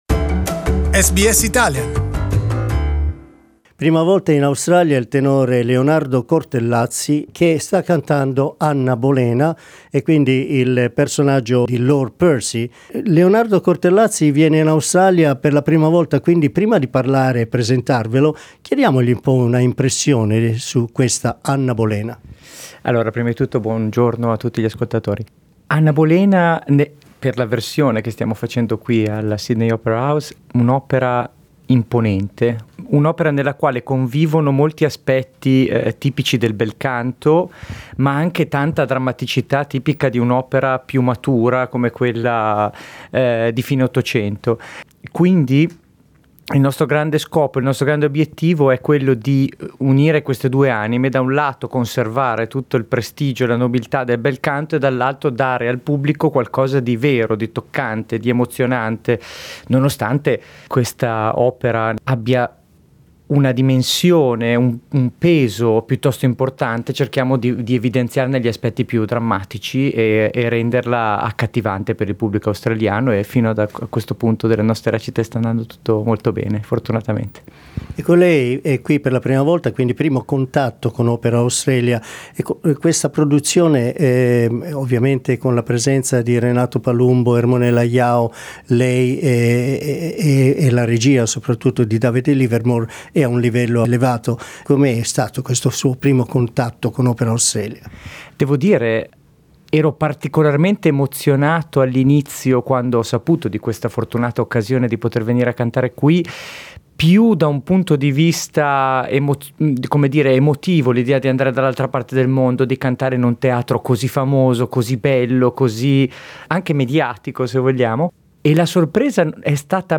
SBS Italian